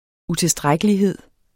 Udtale [ uteˈsdʁagəliˌheðˀ ]